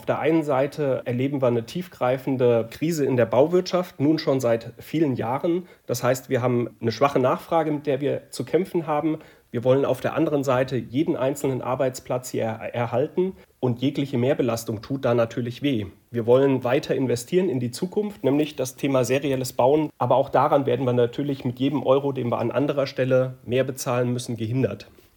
O-Ton: Willkür macht Hausbau teurer